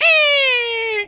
bird_large_squawk_03.wav